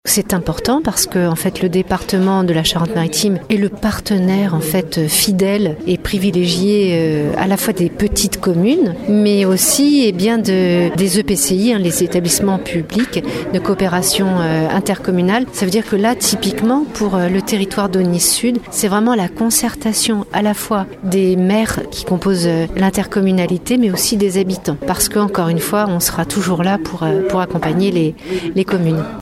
Le Département de la Charente-Maritime est également partie prenante dans cette opération, comme le souligne sa présidente Sylvie Marcilly :